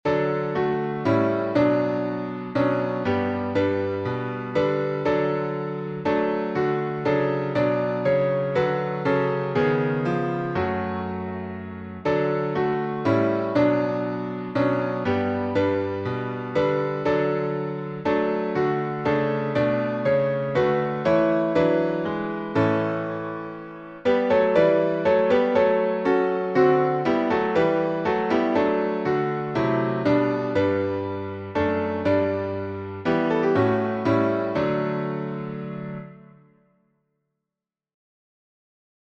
Praise, My Soul, the King of Heaven — D major — Lauda Anima Andrews.
Words from Psalm 103 by Henry Frances Lyte (1793-1847), 1834Tune: LAUDA ANIMA (Andrews) by Mark Andrews (1875-1939)Key signature: D major (2 sharps)Time signature: 3/4Meter: 8.7.8.7.8.7.Public Domain1.